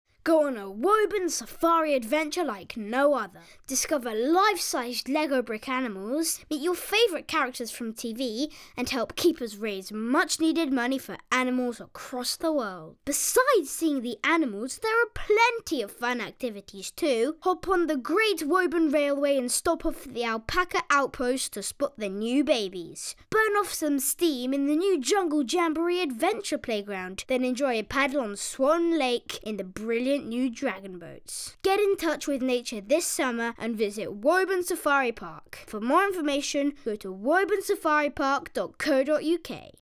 Dubbing Showreel